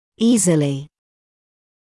[‘iːzɪlɪ][‘иːзили]легко, без труда; несовмненно, бесспорно